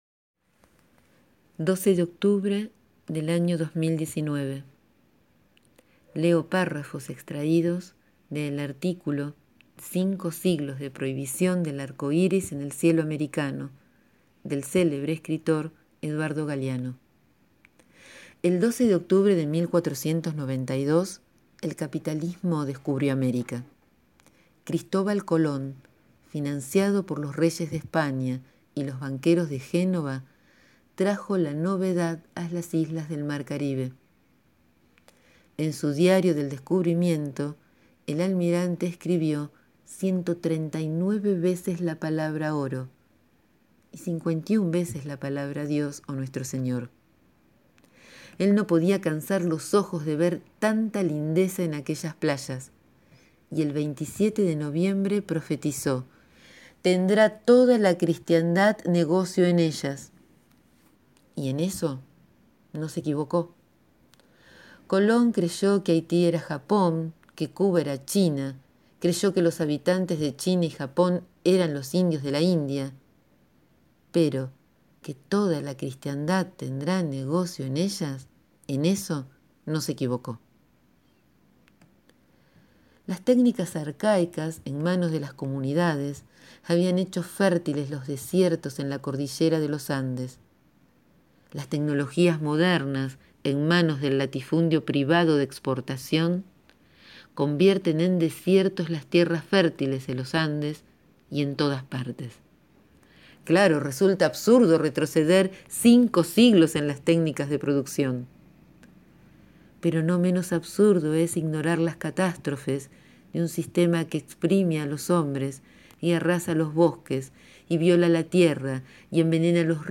Extraigo párrafos, los grabo para compartir aquí.